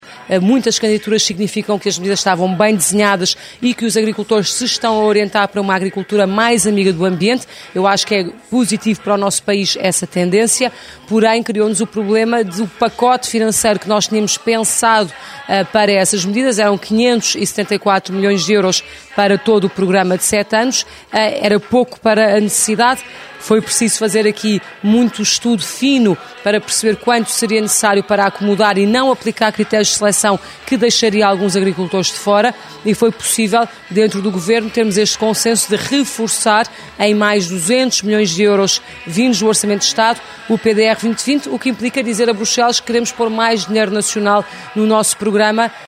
Declarações da Ministra da Agricultura, Assunção Cristas, que sexta-feira falou em Macedo de Cavaleiros.